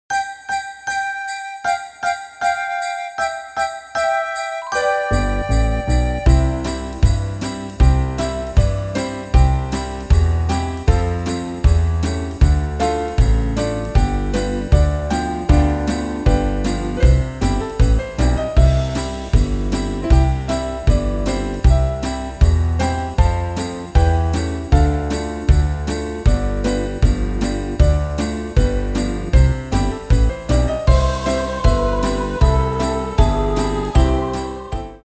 Rubrika: Vánoční písně, koledy